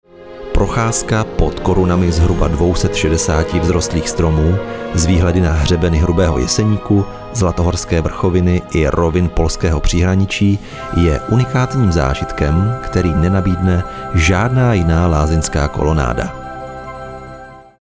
Mužský voiceover do Vašich videí
Nabízím mužský voiceover středního věku.